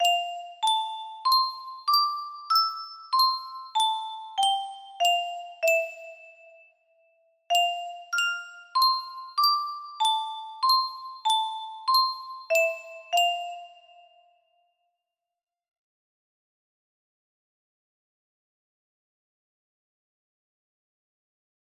Lullaby music box melody
Grand Illusions 30 (F scale)